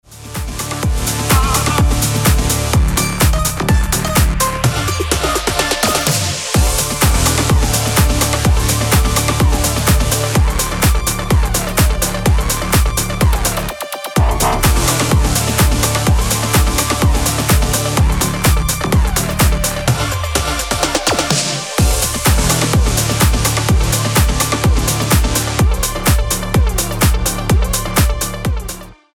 • Качество: 320, Stereo
жесткие
мощные
атмосферные
EDM
future house
electro house
Стиль: electro/bass/future house